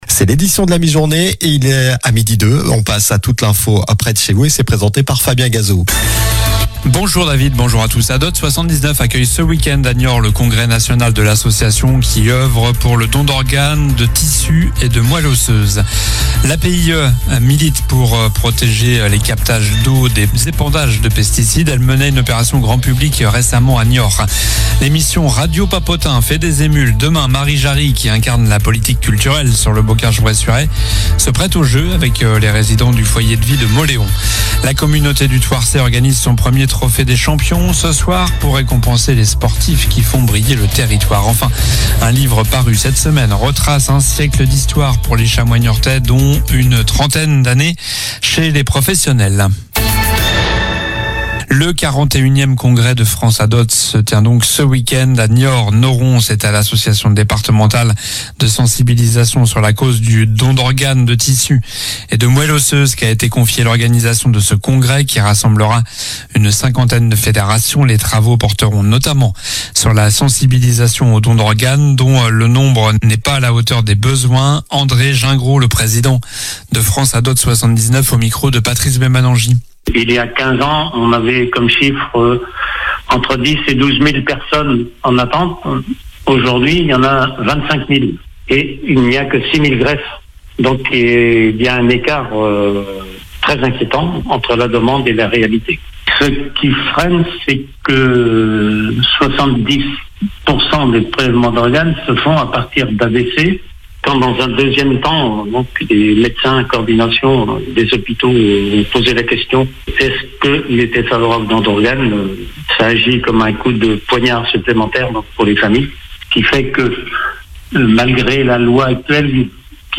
Journal du vendredi 17 octobre (midi)